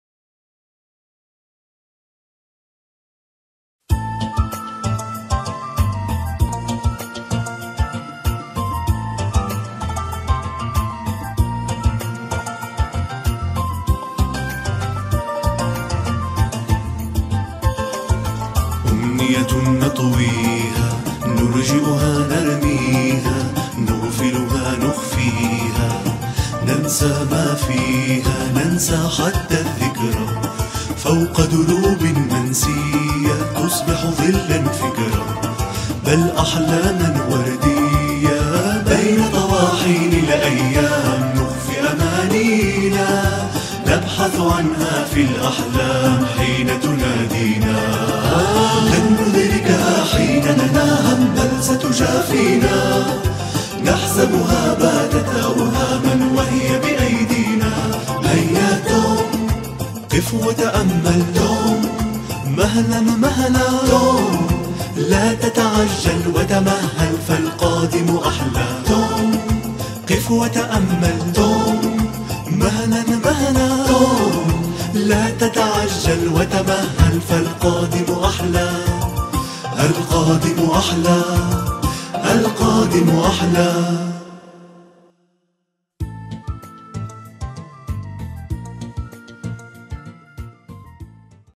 توم سوير - الحلقة 1 مدبلجة